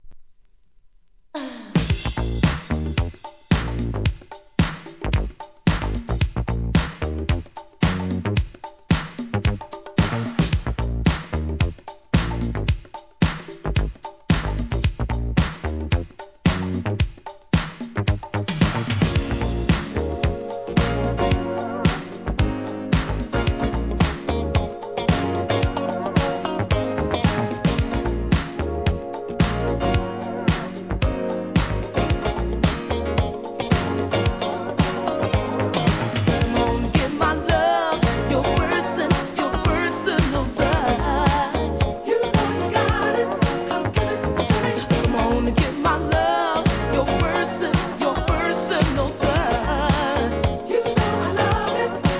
SOUL/FUNK/etc...
人気のダンスナンバー